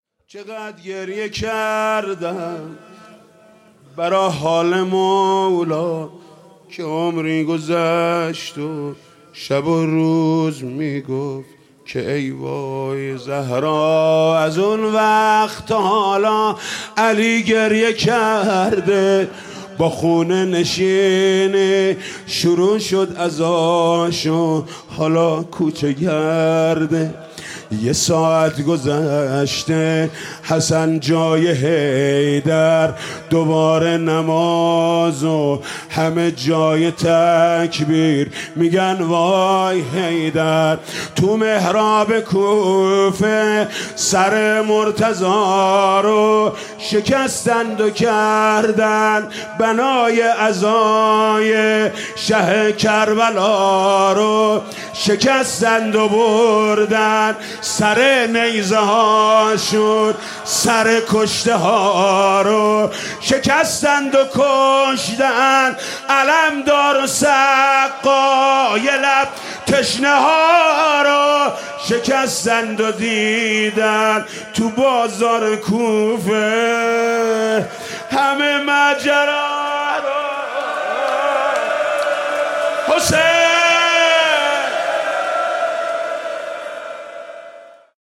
«شب نوزدهم» روضه: چقدر گریه کردم به حال مولا